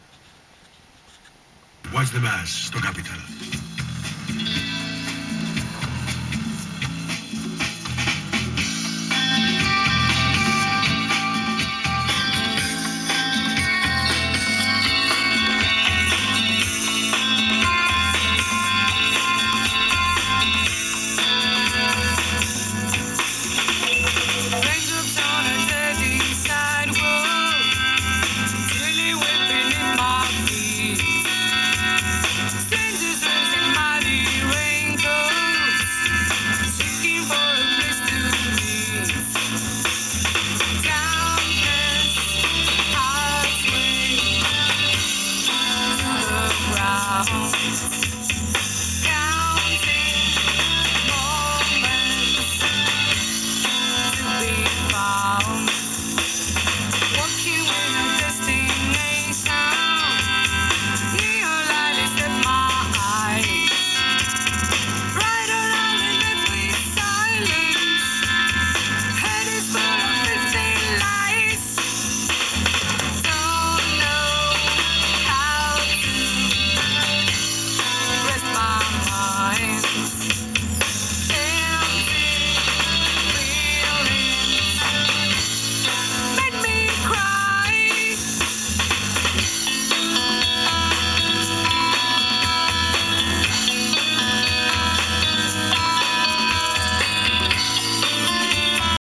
Запись изначально с радио лет 25-27 назад .